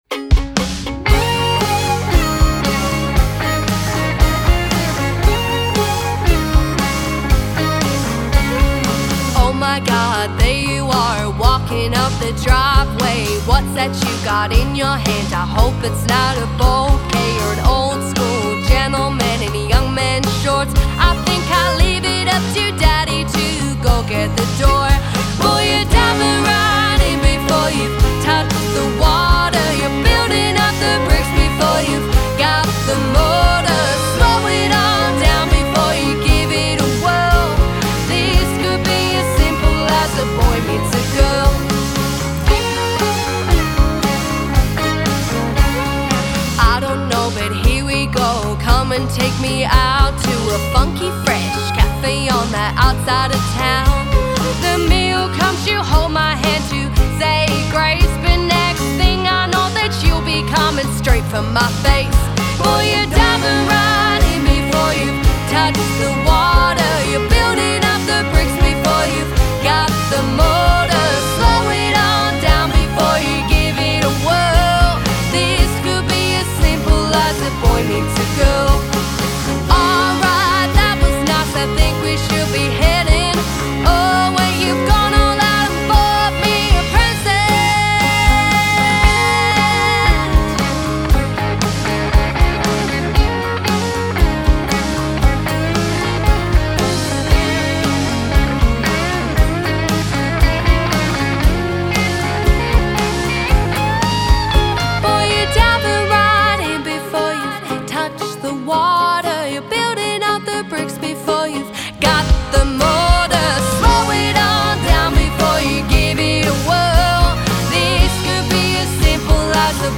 at Hot Plate Studios in Sancrox NSW